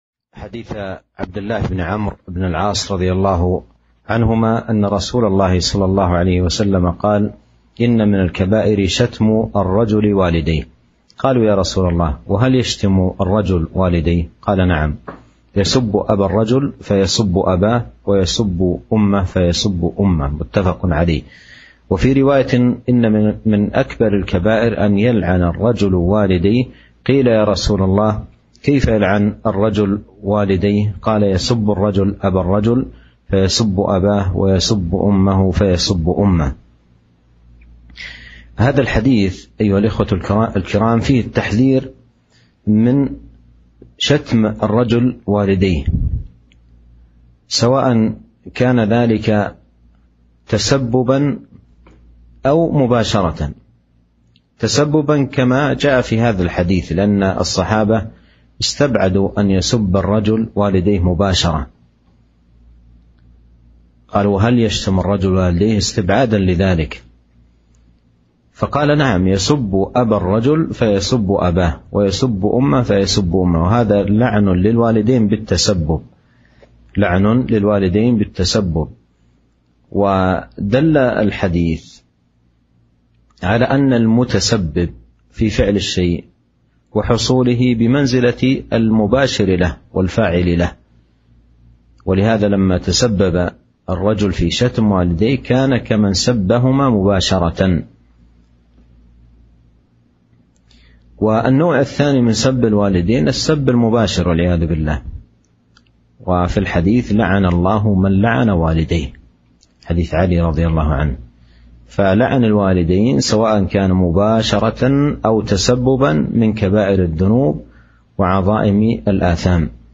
شرح حديث من الكبائر شتم الرجل والديه